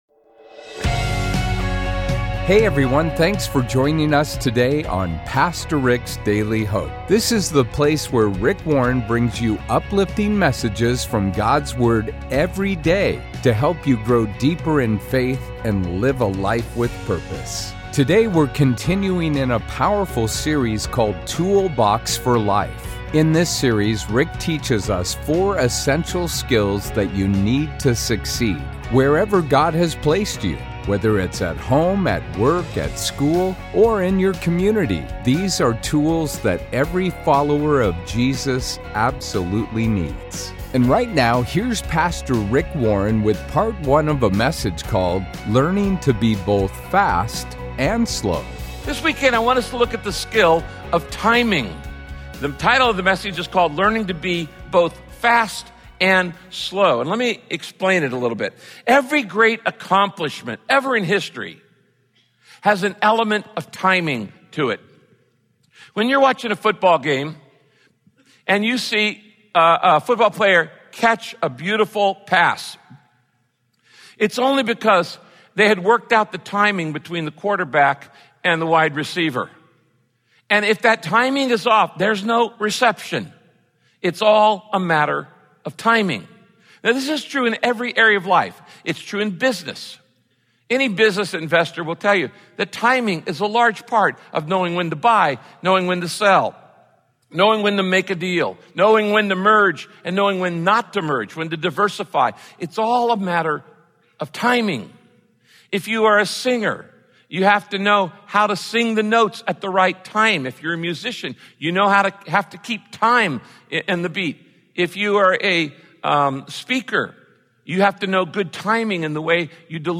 In this broadcast, Pastor Rick explains how God sometimes expects you to move fast, such as when you need to ask for forgiveness, when you’re feeling tempted, w…